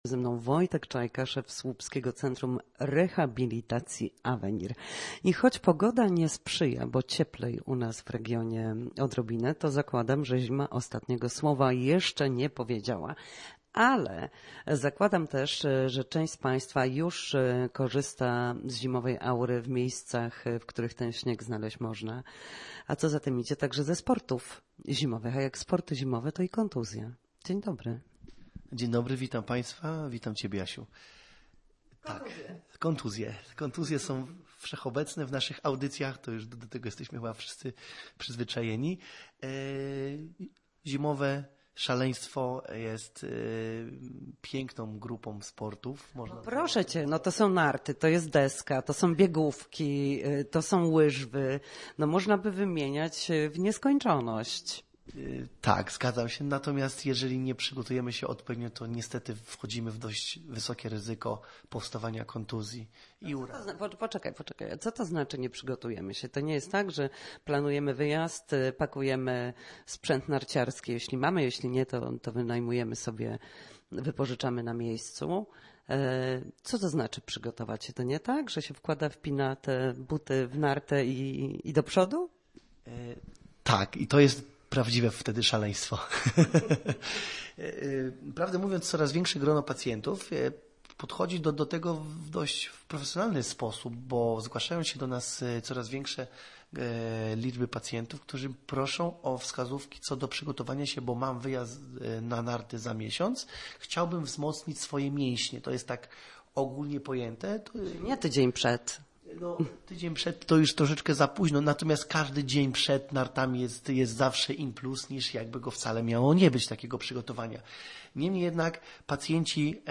W każdą środę, w popołudniowym Studiu Słupsk Radia Gdańsk, dyskutujemy o tym, jak wrócić do formy po chorobach i urazach.